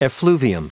Prononciation du mot effluvium en anglais (fichier audio)
Prononciation du mot : effluvium
effluvium.wav